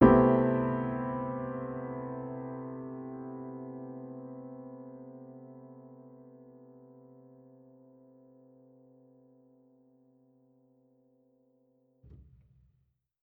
Index of /musicradar/jazz-keys-samples/Chord Hits/Acoustic Piano 2
JK_AcPiano2_Chord-Cmaj13.wav